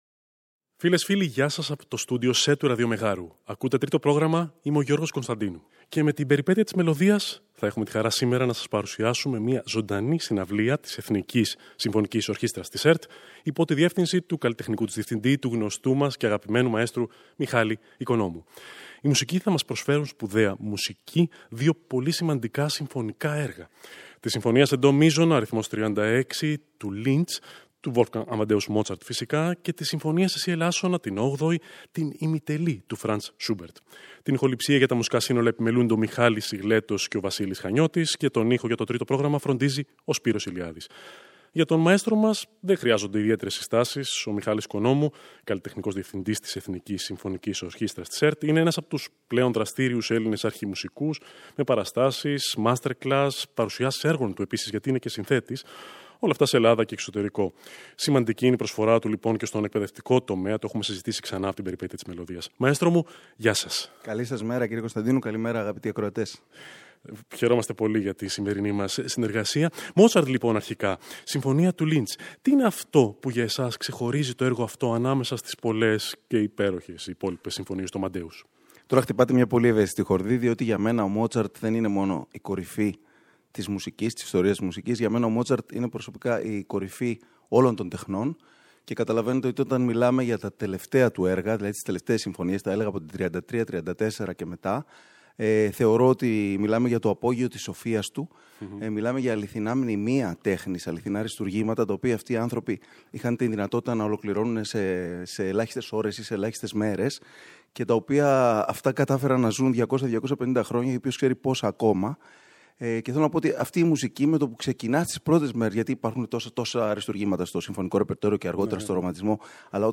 Απευθείας μετάδοση από το στούντιο C της ΕΡΑ
Από τον Βιεννέζικο κλασικισμό στον γερμανικό ρομαντισμό
Η συναυλία θα μεταδοθεί απευθείας από το Στούντιο C της Ελληνικής Ραδιοφωνίας.